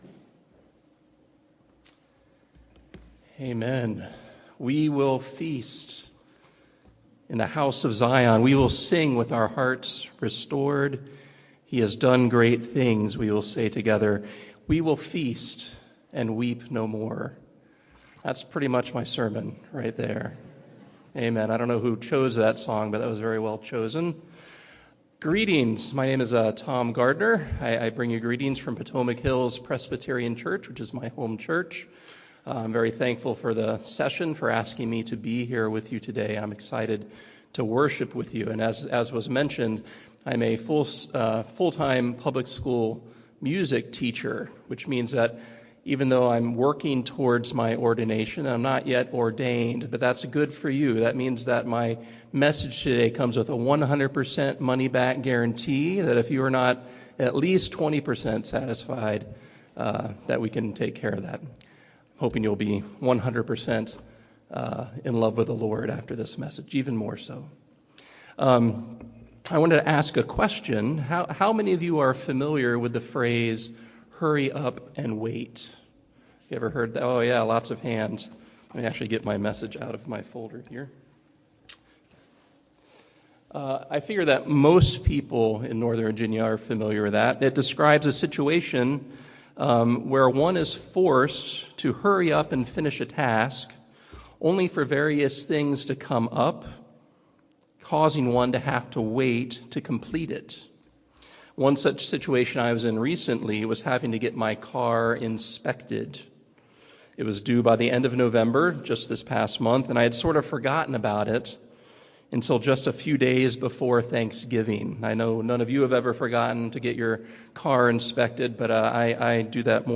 The Coming Day: Sermon on Jeremiah 23:5-8 - New Hope Presbyterian Church